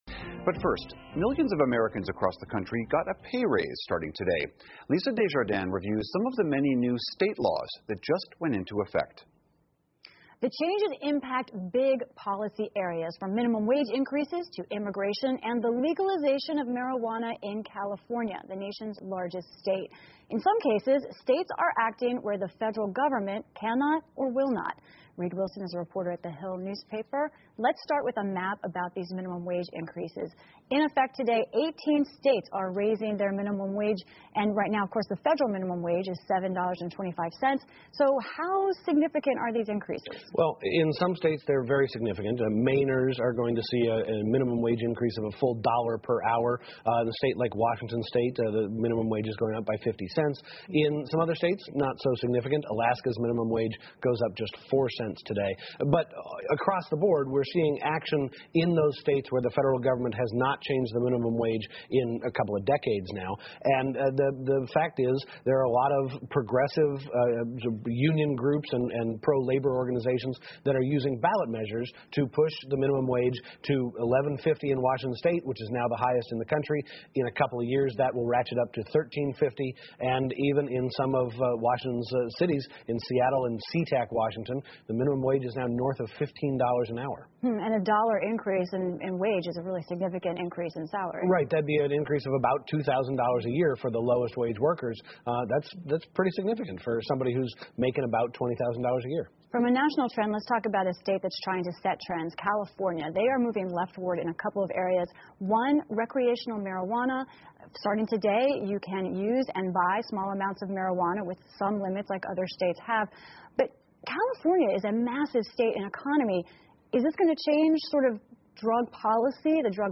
PBS高端访谈:成千上万人加薪 众多法律今年出台 听力文件下载—在线英语听力室